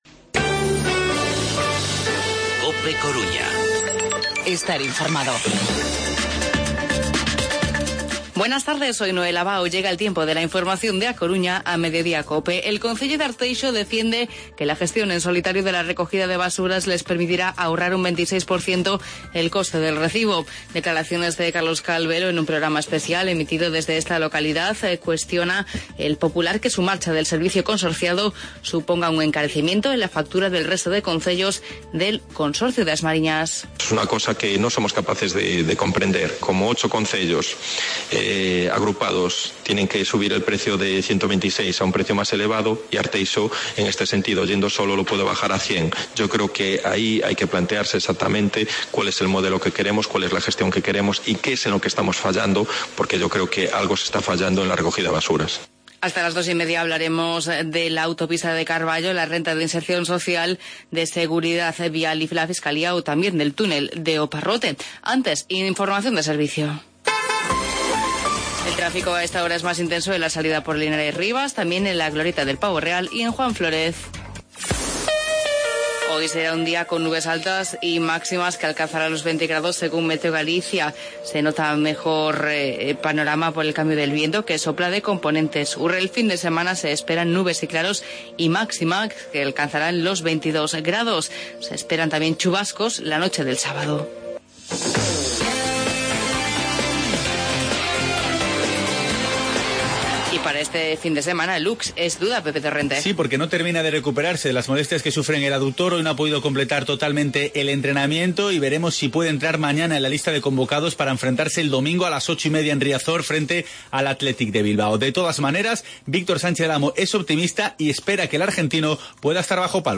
Informativo Mediodía COPE Coruña viernes, 16 de octubre de 2015